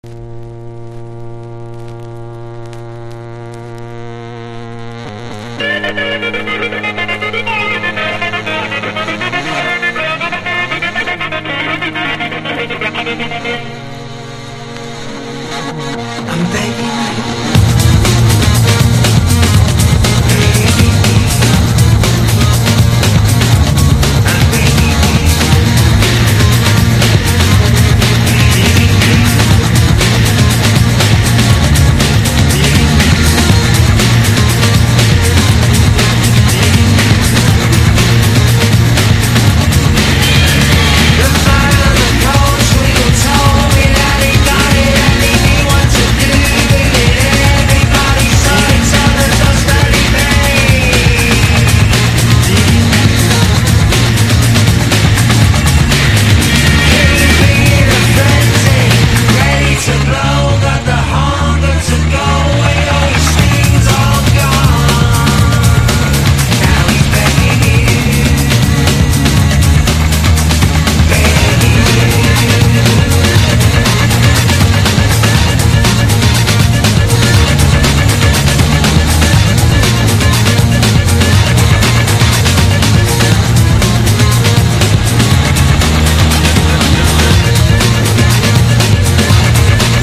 1. 90'S ROCK >